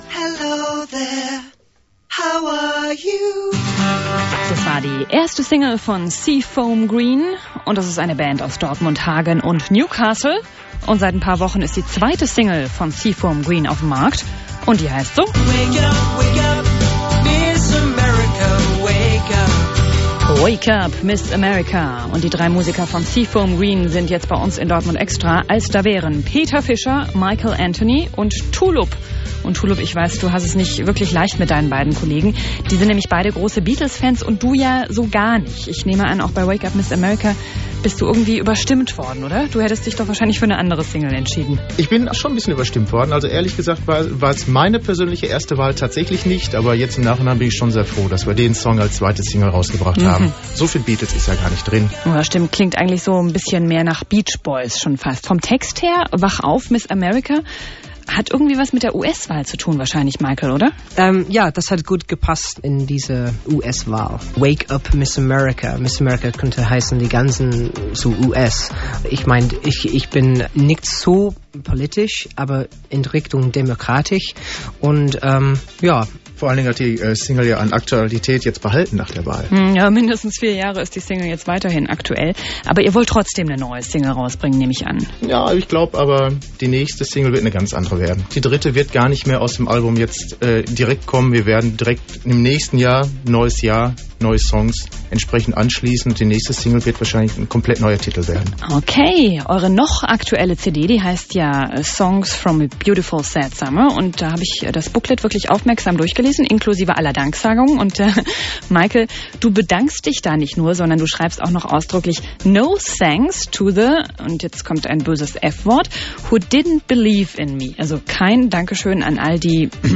SFG im Radio...